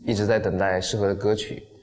Text-to-Speech
Chinese_Audio_Resource / 蔡徐坤 /无背景音乐的声音 /一直在等待适合的歌曲.wav